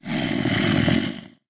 minecraft / sounds / mob / zombie / say1.ogg